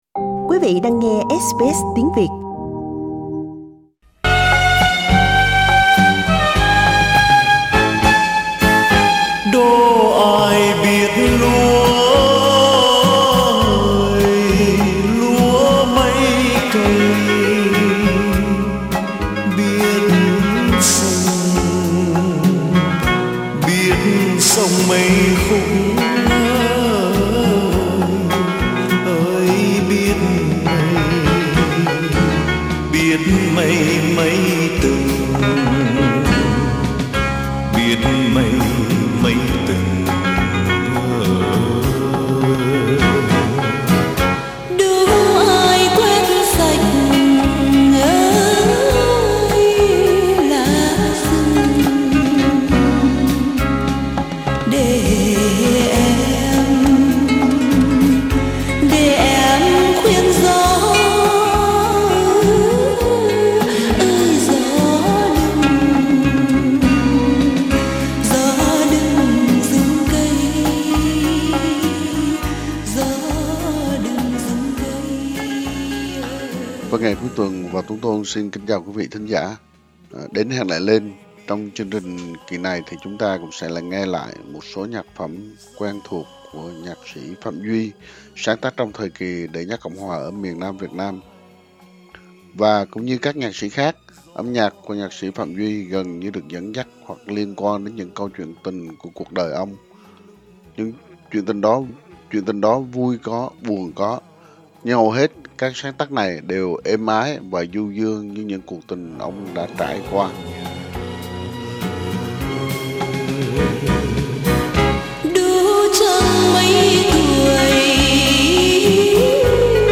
những biến thể của điệu hát ru hát ả đào